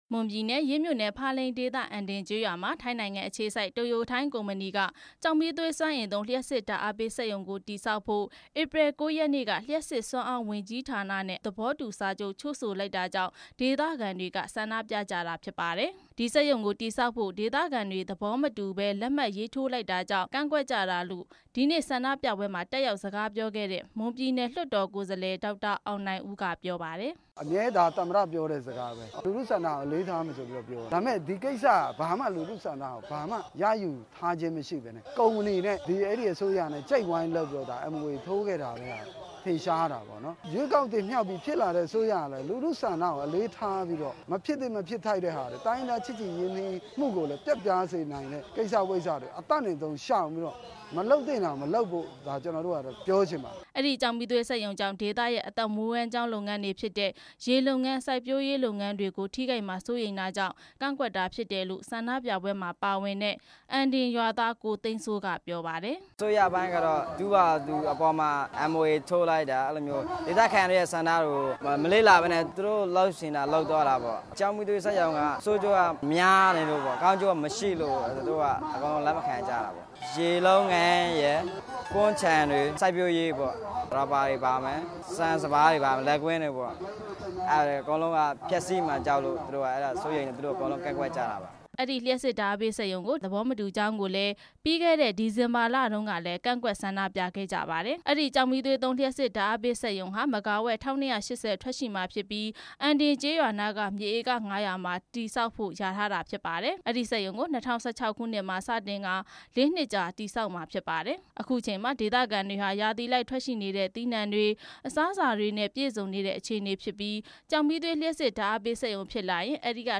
အံဒင်ကျောက်မီးသွေးစက်ရုံ စီမံကိန်း ဆန္ဒပြတဲ့အကြောင်း တင်ပြချက်